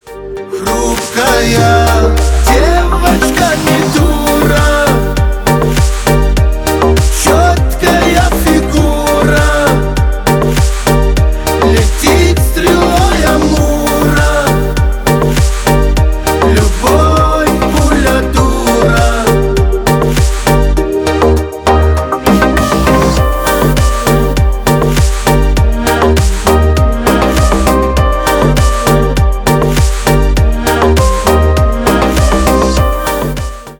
кавказские
поп